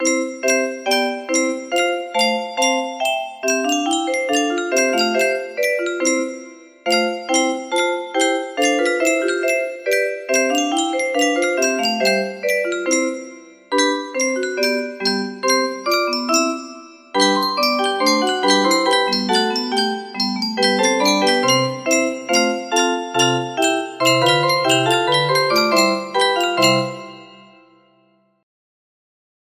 Jesus Christ is Risen Today music box melody